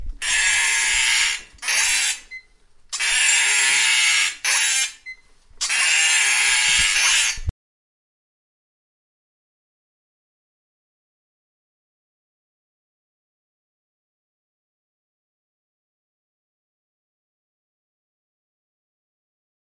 机器
描述：滑雪板打蜡器
声道立体声